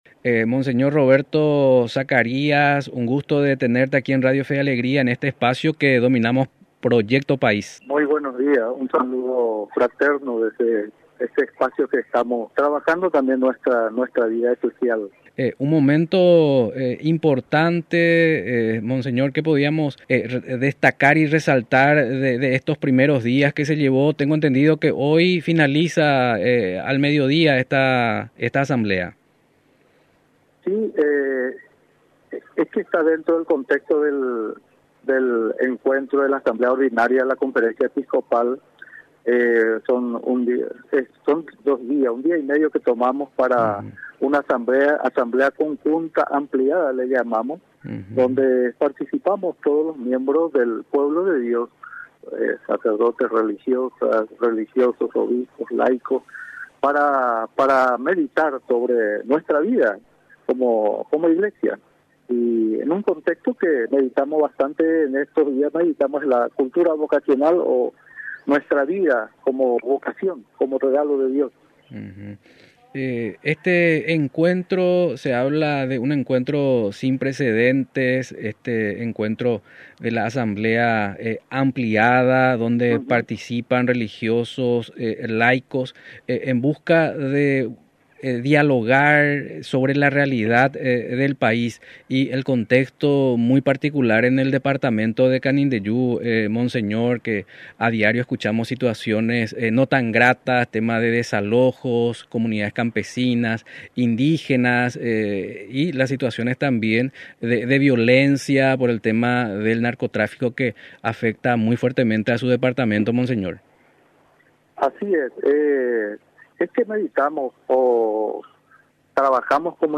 Monseñor Roberto Zacarías, obispo de la Diócesis de Canindeyú y responsable de la Pastoral de Juventud, destacó la cultura vocacional, el desafío de la Iglesia en Paraguay y el acompañamiento a los jóvenes. Entrevista realizada en el programa Proyecto País.